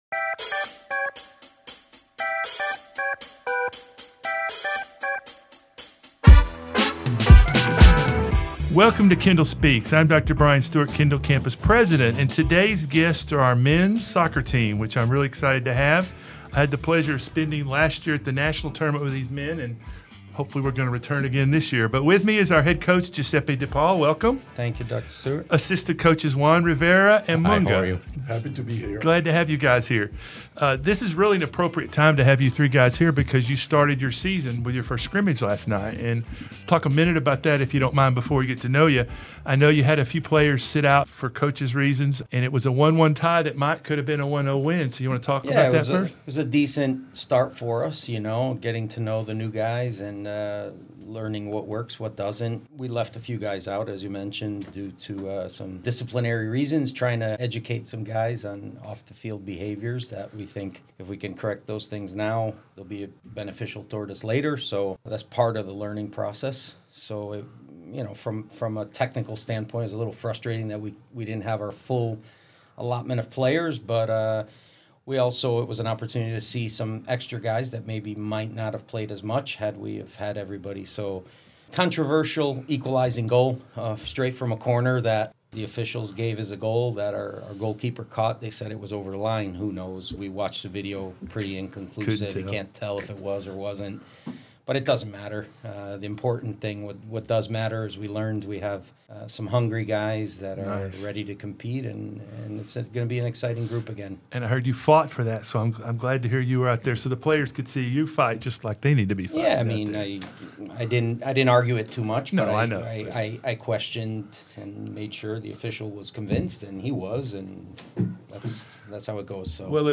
Even if you don't know a bicycle kick from a corner kick, this is a fascinating coversation that delves into the careers of the coaches, their training methodologies, and their recruitment processes, among other topics.